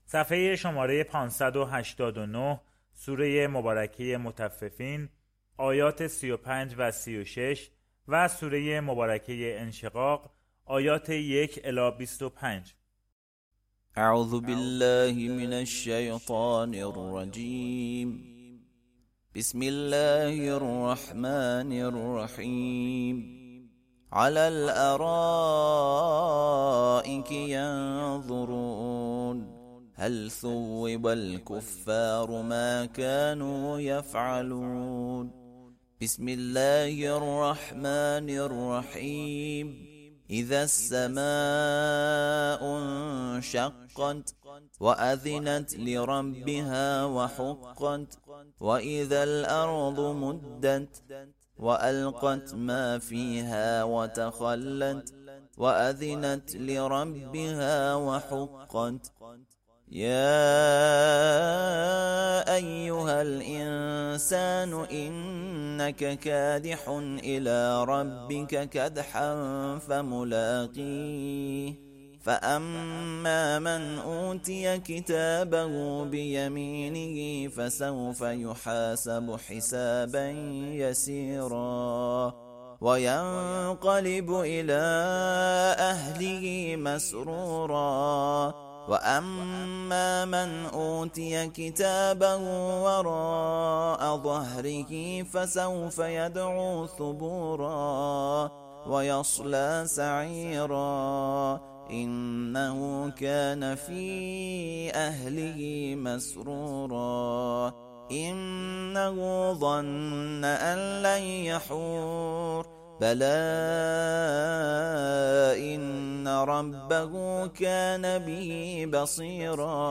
ترتیل صفحه ۵۸۹ از سوره مطففین و انشقاق (جزء سی)